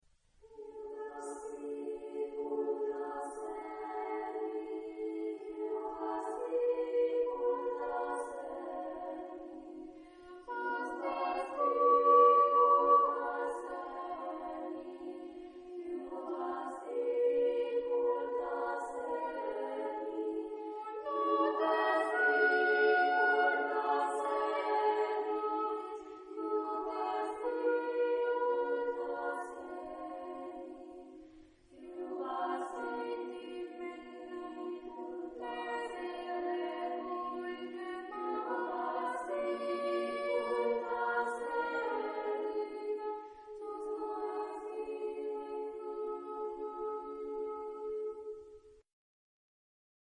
Mood of the piece: sorrowful
Type of Choir: SSA  (3 children voices )
Soloist(s): Treble  (1 soloist(s))
Tonality: D major ; B minor